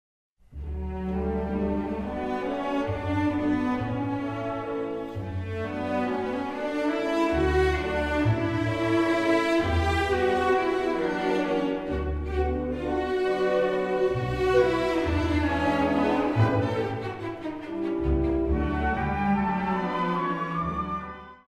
Allegro con grazia, re maggiore – si minore
Valzer in 5/4 con Trio
tema valzer bt. 1-4 violoncelli 11 esempio 11 Midi (formato WMV)   esempio multimediale (formato formato flash)
bt. 1- 9 violoncelli con
esempio 11 orchestrale (formato MP3)